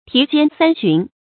蹄間三尋 注音： ㄊㄧˊ ㄐㄧㄢ ㄙㄢ ㄒㄩㄣˊ 讀音讀法： 意思解釋： 指馬奔走時，前后蹄間一躍而過三尋。形容馬奔跑得快。